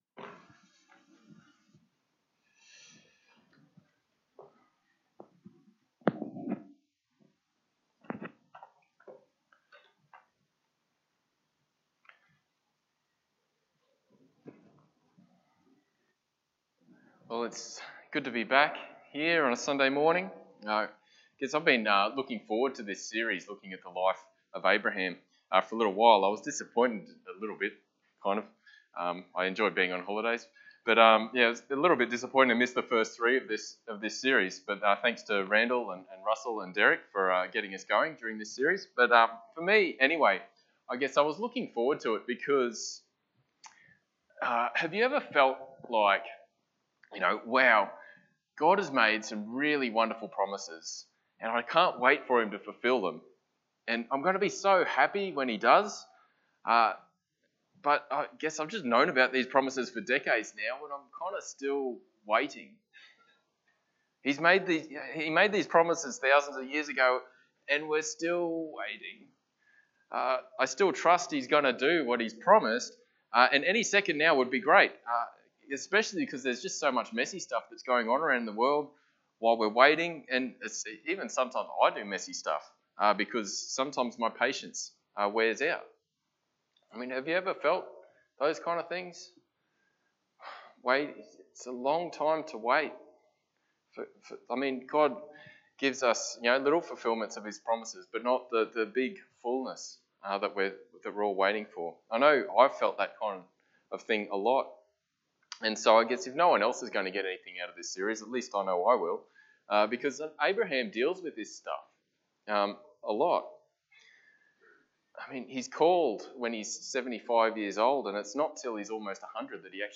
Passage: Genesis 14 Service Type: Sunday Morning